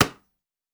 Glove Catch Normal.wav